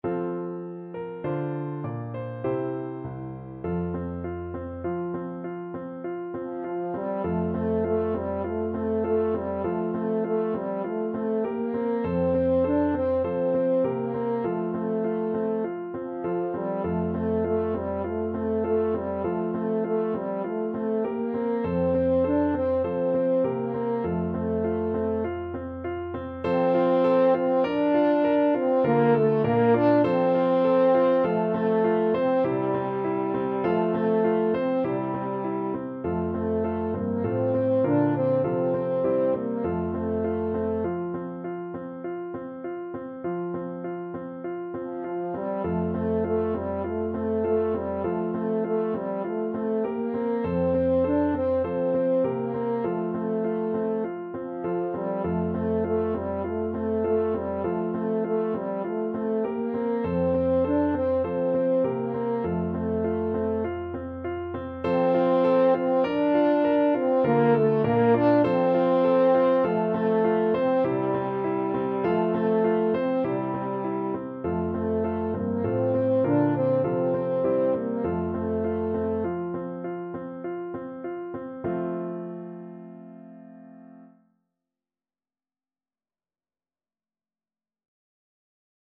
Christmas Christmas French Horn Sheet Music Es ist fur uns eine Zeit angekommen
French Horn
4/4 (View more 4/4 Music)
Moderato